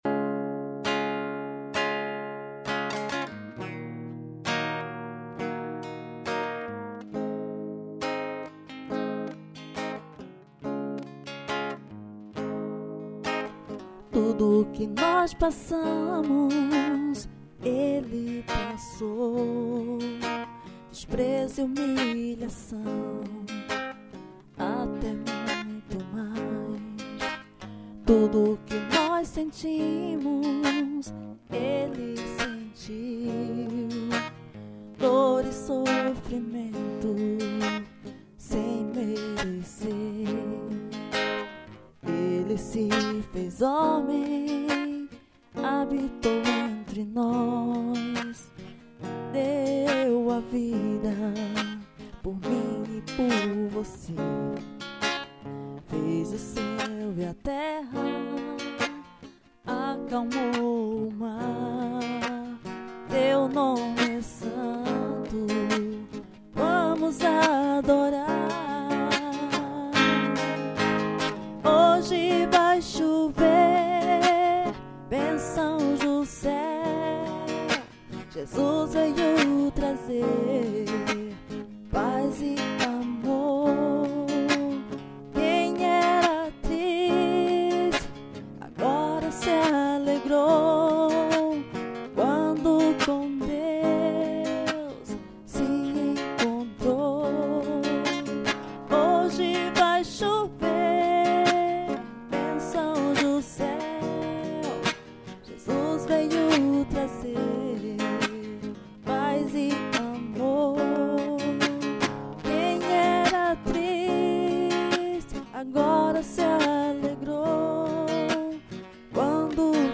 voz
violao